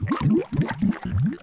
drink_potion.wav